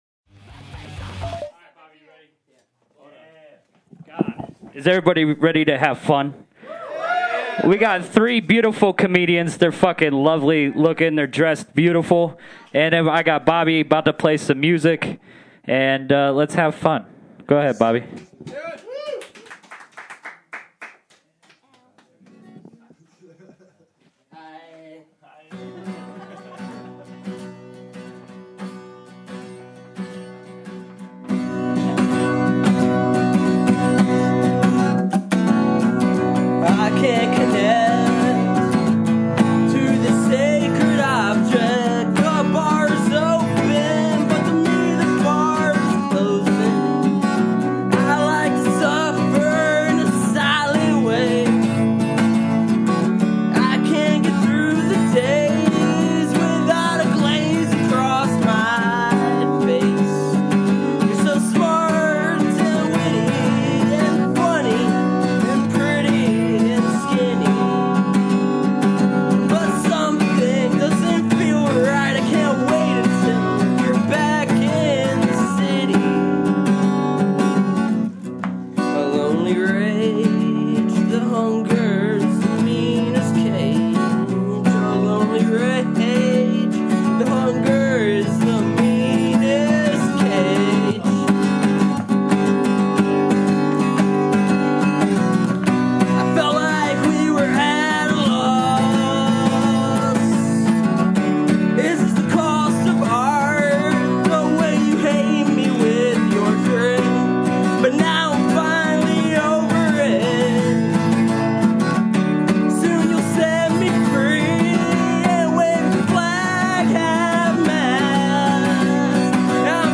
On October 5th 2014 we presented the very first Basement Comedy Show.
If you missed the live stream on LWM Radio, here is the show in it’s entirety.